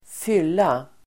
Uttal: [²f'yl:a]